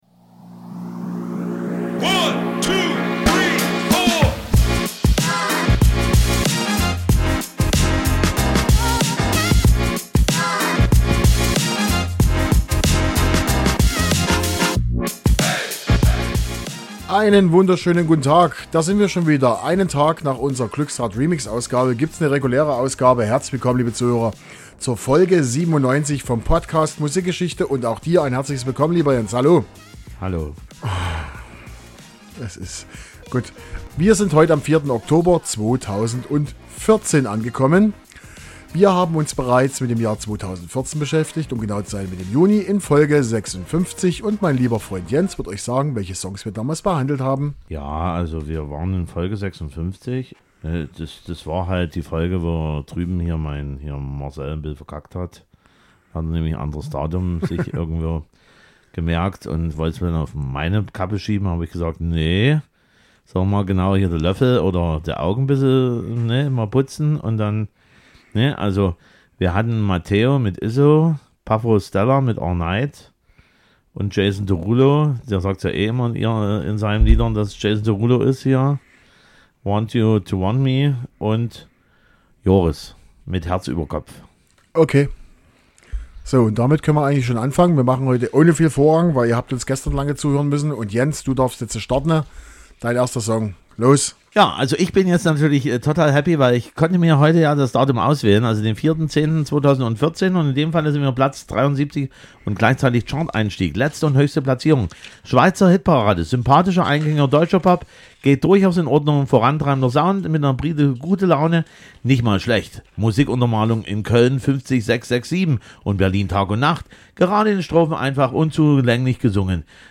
Deutscher Poprock, Countrypop, Popmusik und eine Dancenummer. Alles ziemlich bunt. Endquiz ist auch wieder dabei.